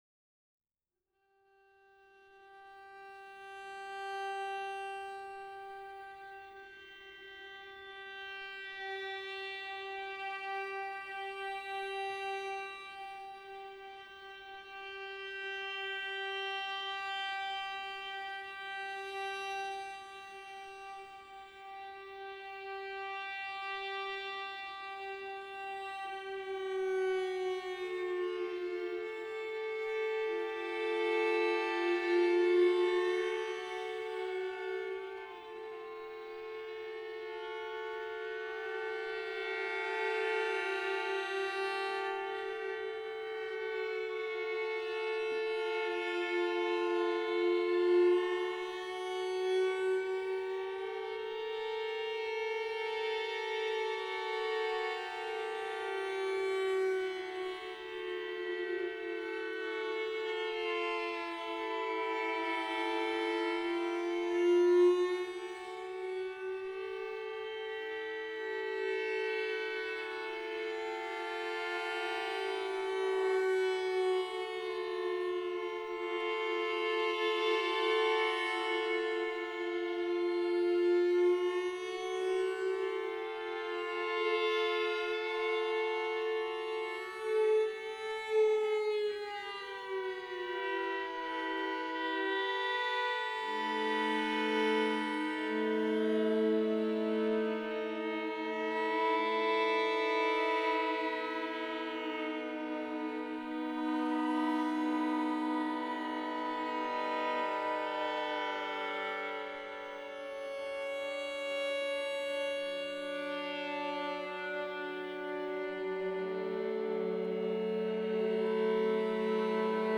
(# string trio)